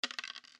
dice.mp3